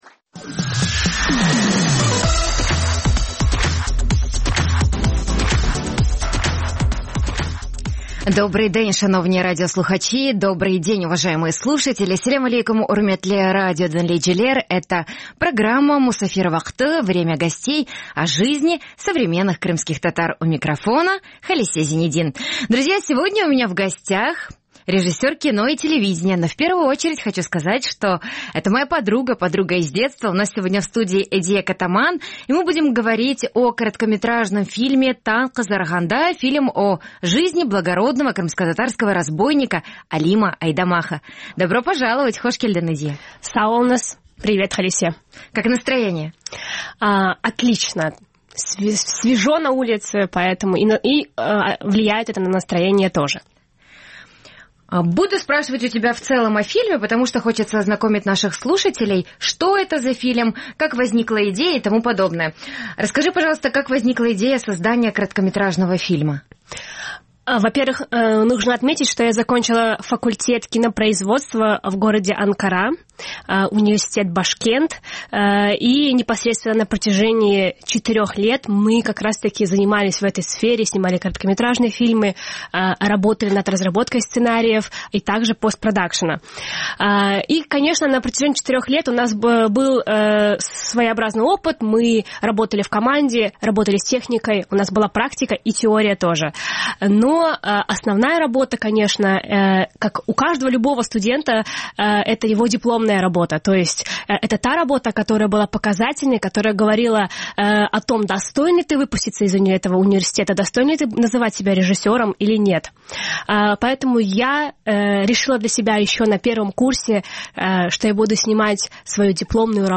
Наша гостья рассказывает о создании короткометражного фильма о благородном крымскотатарском разбойнике «Танъ къызаргъанда».